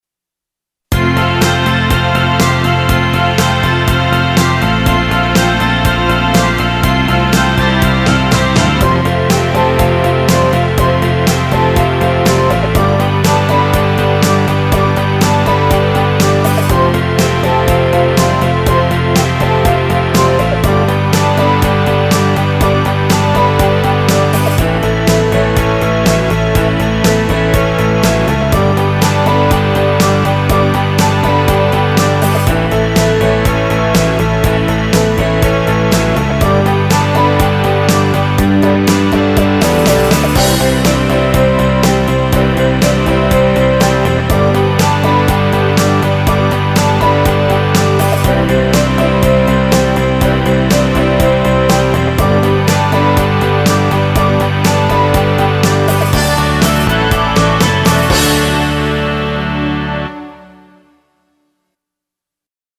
80s pop band